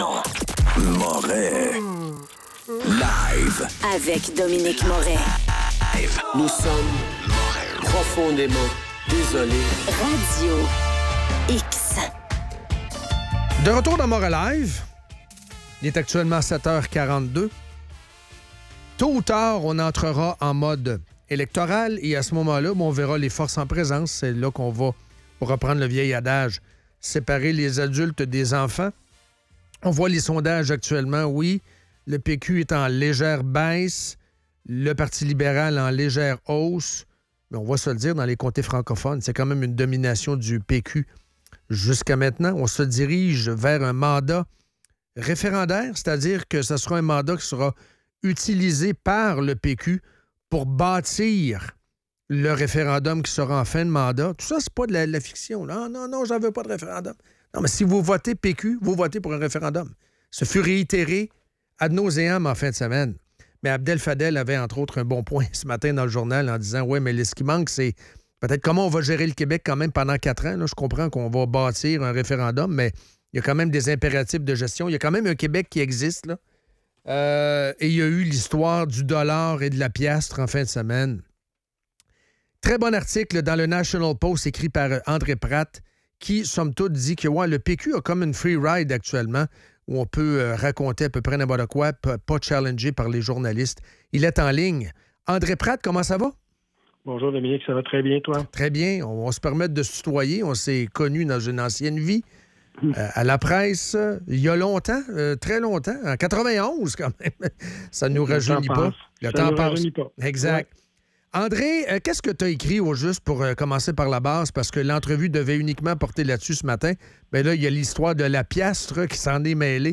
Entrevue avec André Pratte